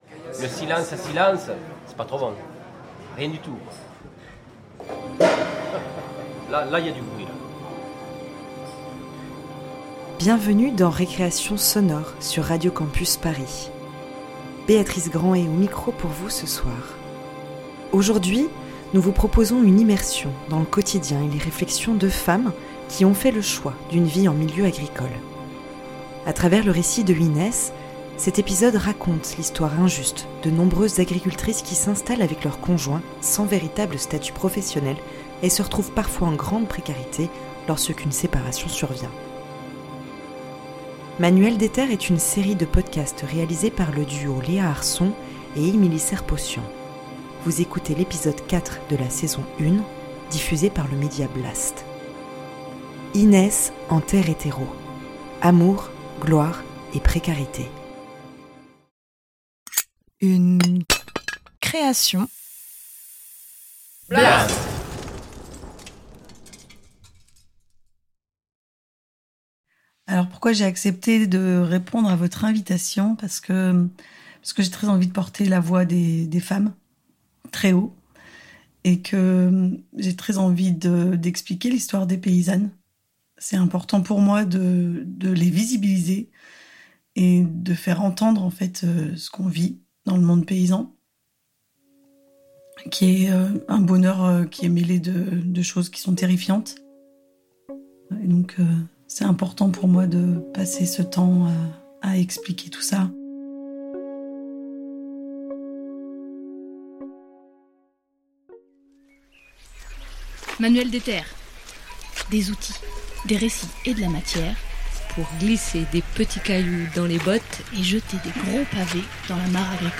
Aujourd’hui nous partons à Saillant, dans la Drôme, au milieu des champs et de la campagne.
Création sonore